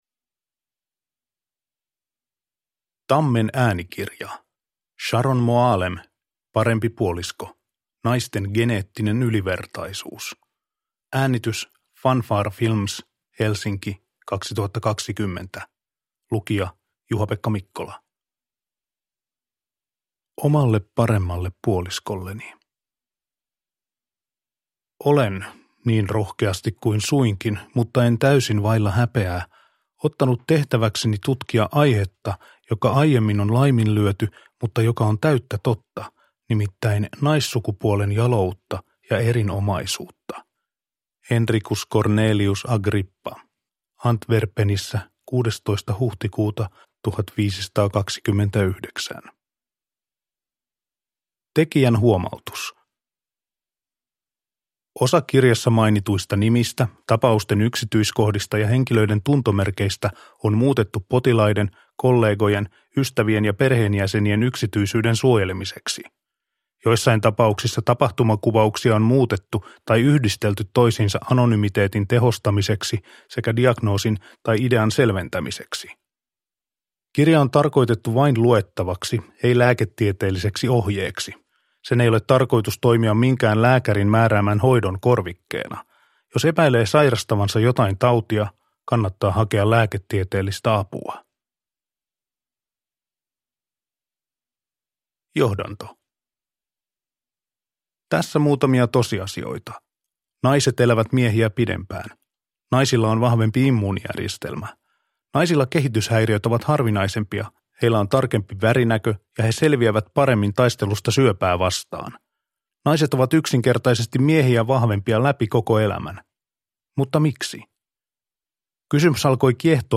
Parempi puolisko – Ljudbok – Laddas ner